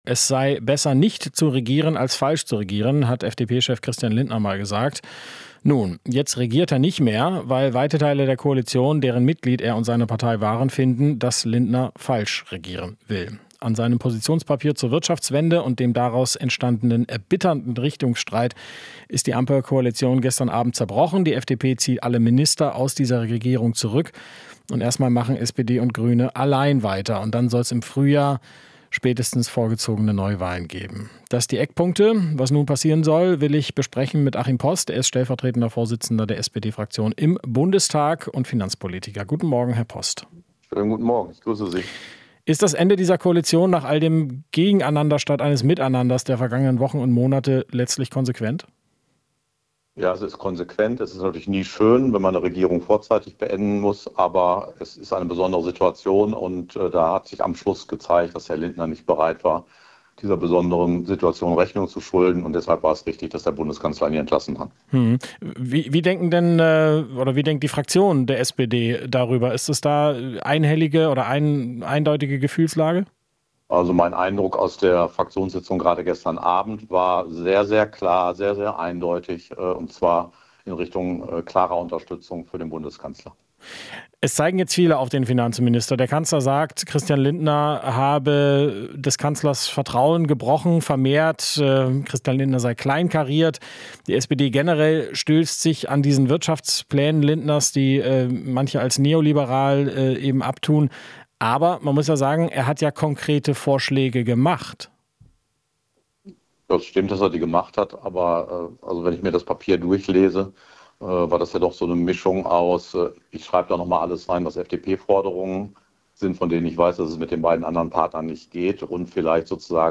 Interview - Ampel-Koalition nach drei Jahren am Ende